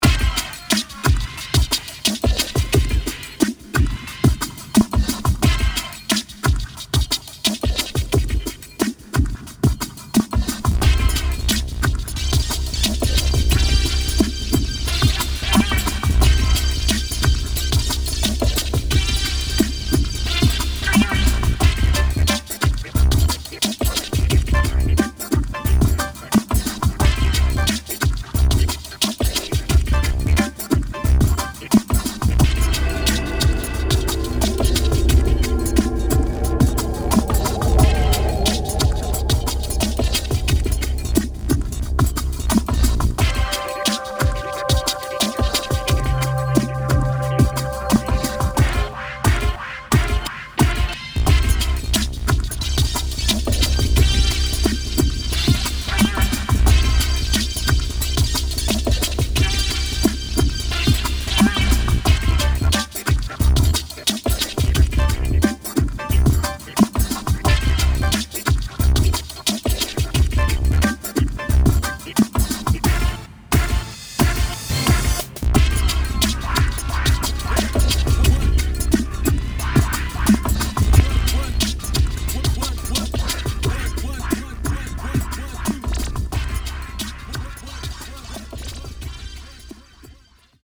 ヒップホップ音楽
ゲームミュージック等に用いられ、リズム感があり楽しい感じを演出します。